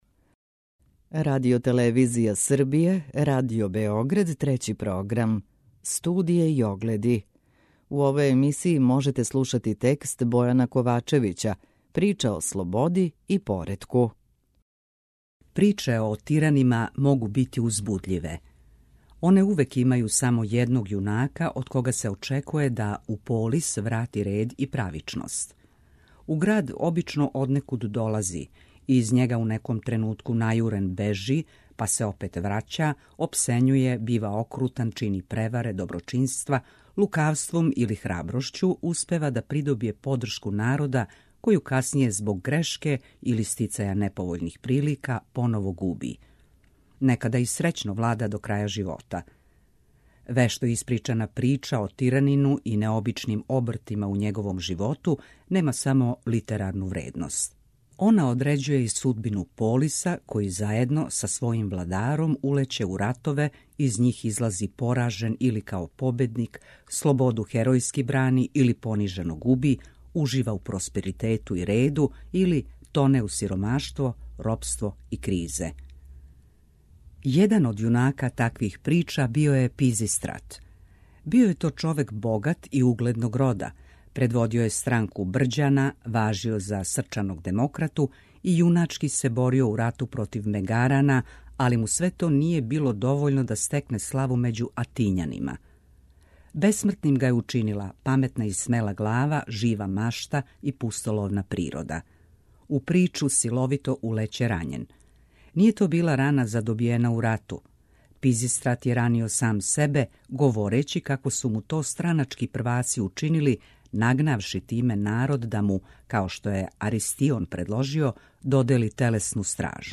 Прва говорна емисија сваке вечери од понедељка до петка.